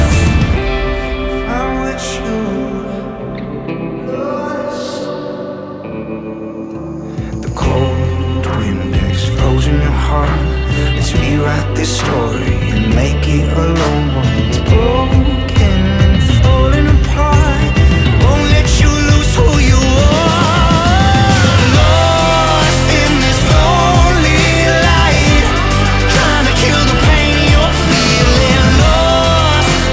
• Indie Pop